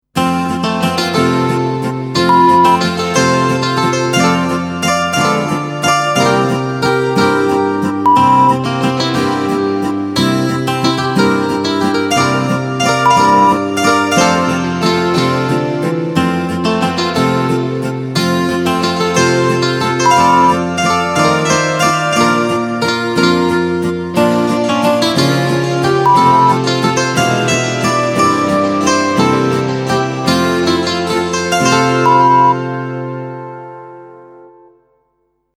Die Hörprobe enthält ein Wasserzeichen (Störtöne).
♫ Tempo: langsam (82 bpm) ♫ Rhythmus: 3/4-Takt